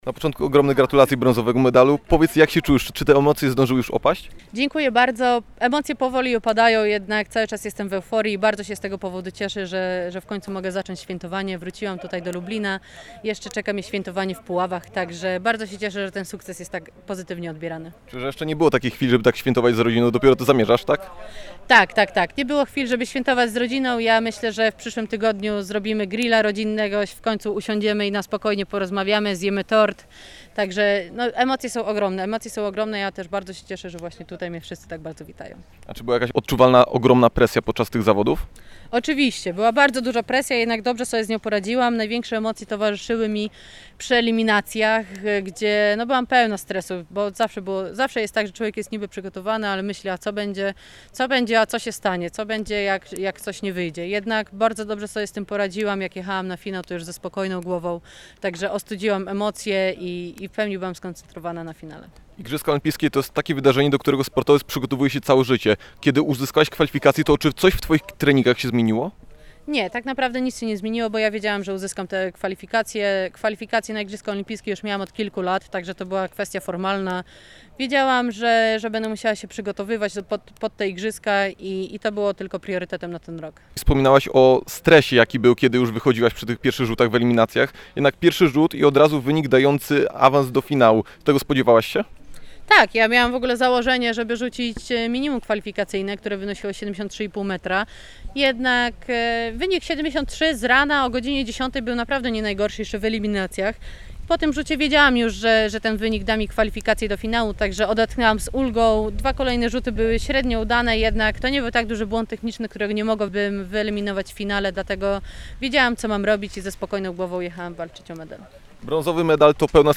O emocjach towarzyszących na igrzyskach, ale i nie tylko opowiedziała Malwina Kopron na poniedziałkowym spotkaniu z kibicami pod pomnikiem Marii Curie-Sklodowskiej. Rozmowę z naszą brązową medalistką znajdziecie poniżej.
Malwina-Kopron-rozmowa.mp3